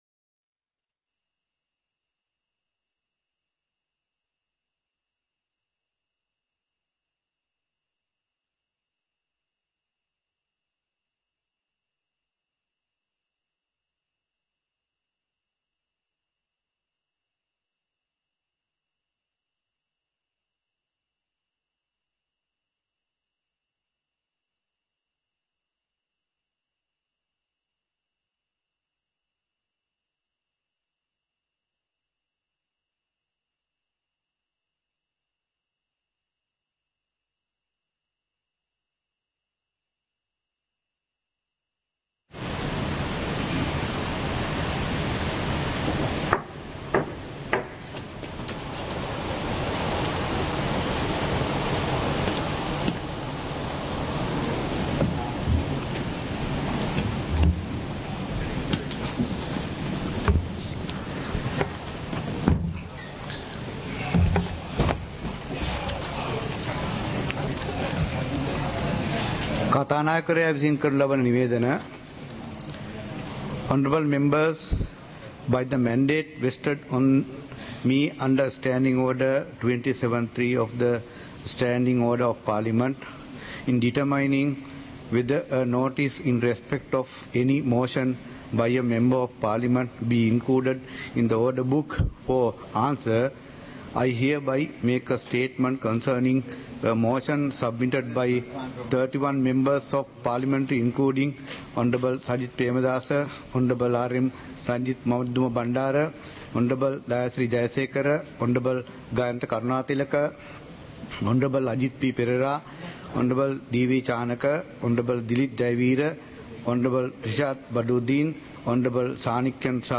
சபை நடவடிக்கைமுறை (2026-01-09)
நேரலை - பதிவுருத்தப்பட்ட